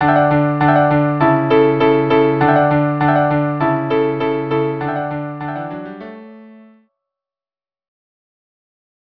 Instrumentation: Violin 1; Violin 2; Viola; Cello